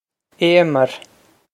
Émer Ay-mur
This is an approximate phonetic pronunciation of the phrase.